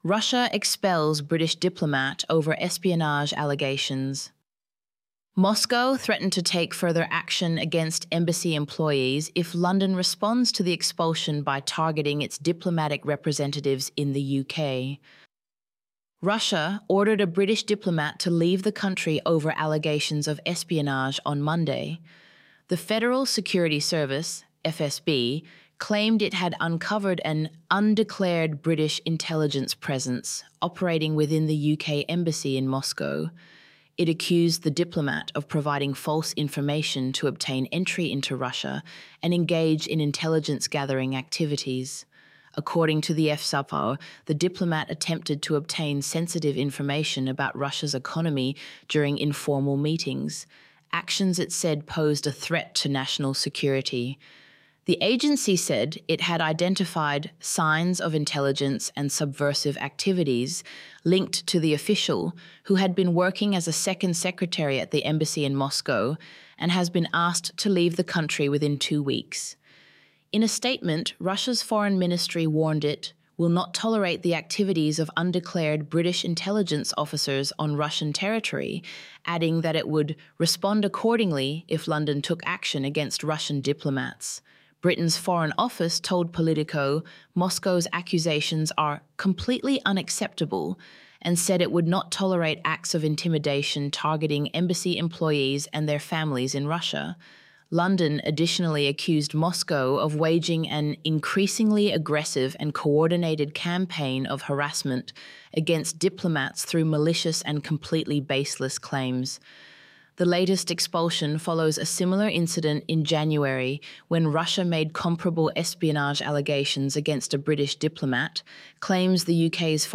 AI generated Text-to-speech